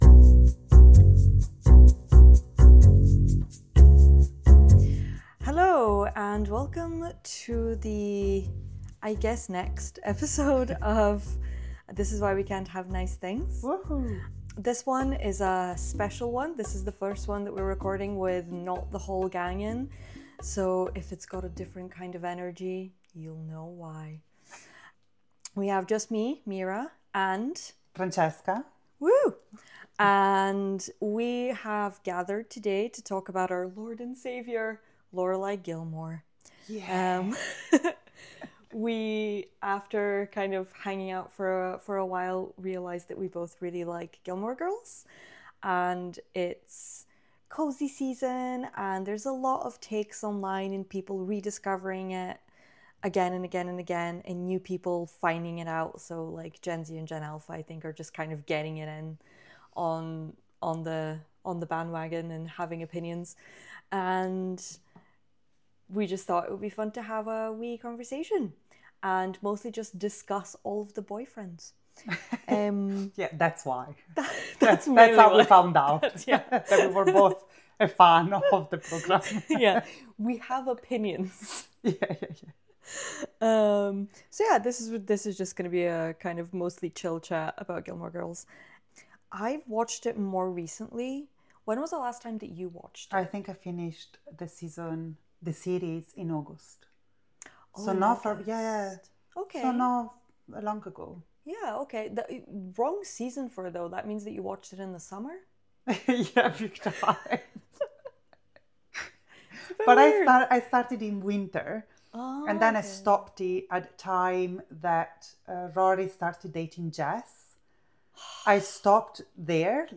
In this (mostly) silly tete-a-tete, two of your favourite podcast gals sit down to discuss one of their favourite shows - Gilmore Girls.